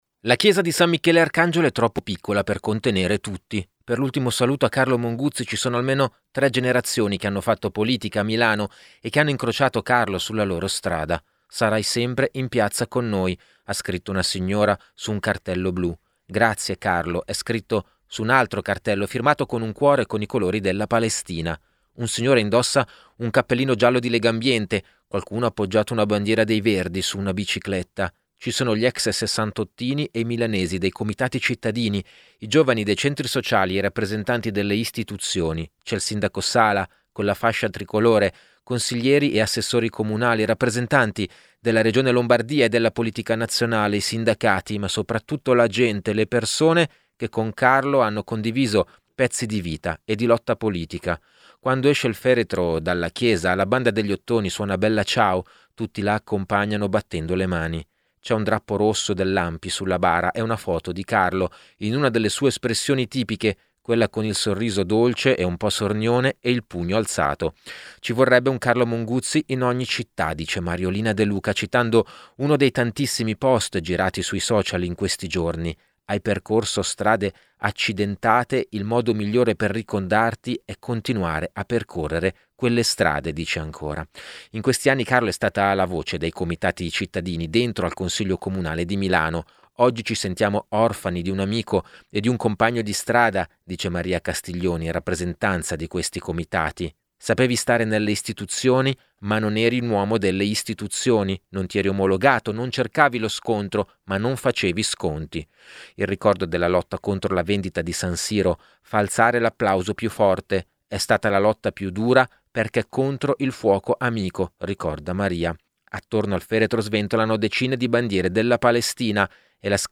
“Ciao Carlo, sarai sempre in piazza con noi”. In migliaia ai funerali di Carlo Monguzzi
Quando esce il feretro dalla chiesa la Banda degli Ottoni suona Bella Ciao, tutti la accompagnano battendo le mani.
Il ricordo della lotta contro la vendita dello stadio di San Siro fa alzare l’applauso più forte.